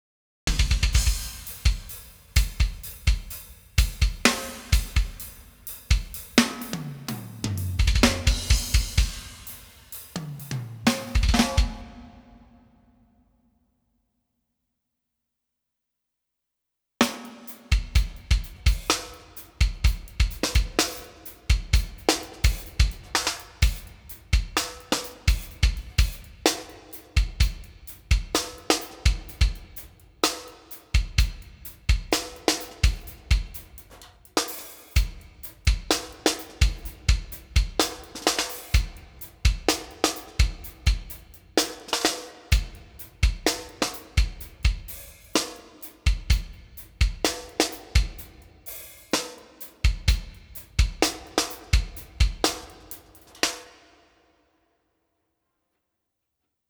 For this comparison I mixed a quick ITB drum mix. Then sent the individual drum channels (16) to the FOLCROM/One of the listed makeup gain amplifiers.